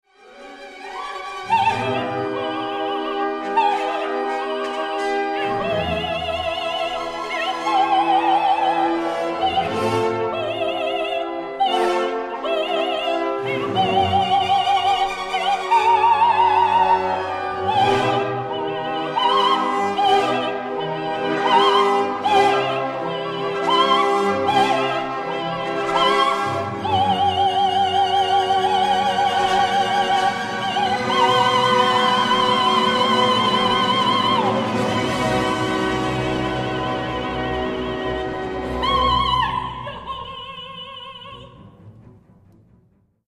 Hoyotoho!
Birgit Nilsson as Brünnhilde in